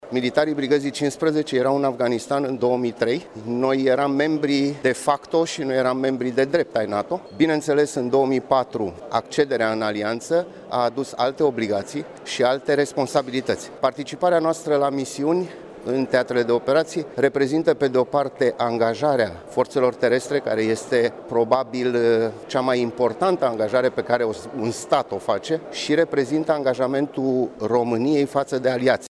Universitatea Alexandru Ioan Cuza din Iaşi a găzduit, astăzi, conferinţa cu tema „România în NATO – 14 ani”.